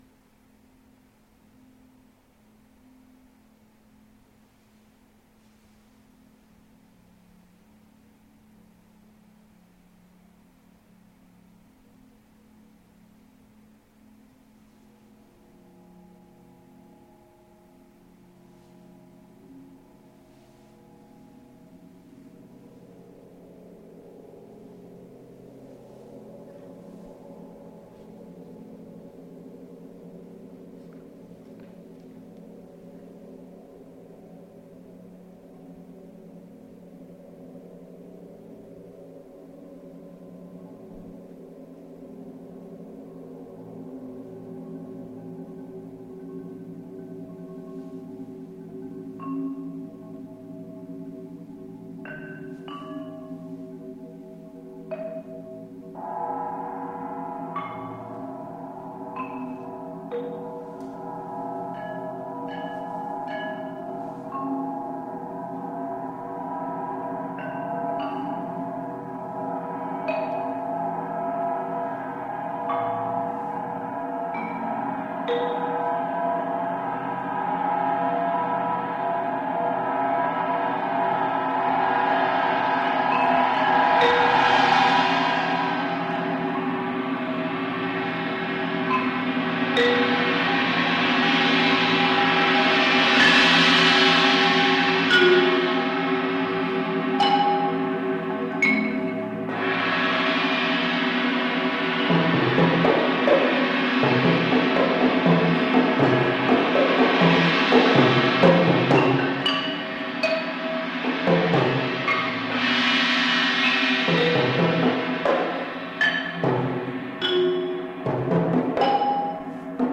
Genre: Percussion Ensemble
# of Players: 8
Percussion 1 (4-octave marimba, cowbell)
Percussion 5 (3-octave vibraphone, 3 suspended cymbals)
Percussion 6 (temple blocks, small tam-tam, orchestra bells)
Percussion 7 (4 tom-toms, large tam-tam)
Percussion 8 (crotales, suspended brake drums)
The first section consists of mostly long sustained notes.